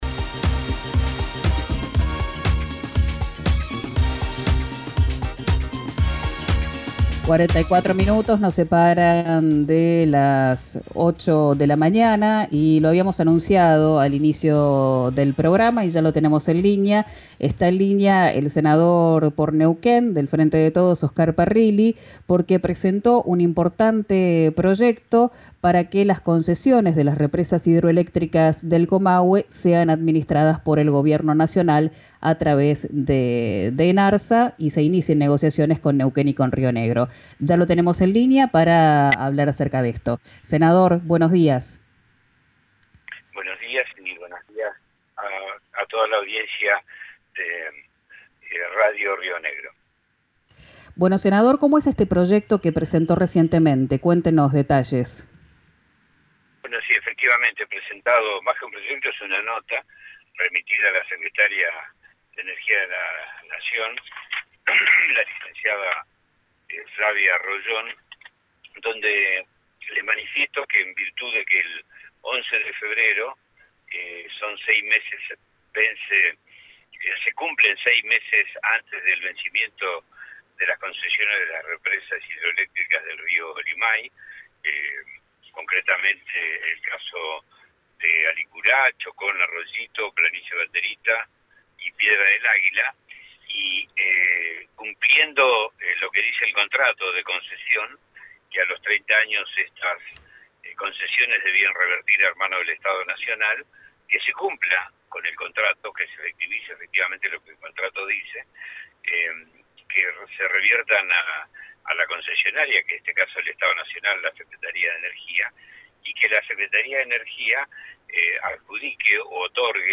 El senador por Neuquén (Frente de Todos), Oscar Parrilli, insistió en que la administración y mantenimiento de las represas hidroeléctricas debe estar en manos del Estado. En diálogo con “Quien dijo verano” por RÍO NEGRO RADIO aseguró que le envió una nota a la secretaria de Energía de la Nación, Flavia Royón, ante el vencimiento de las concesiones sobre los ríos Limay -Chocón, por ejemplo- y Neuquén.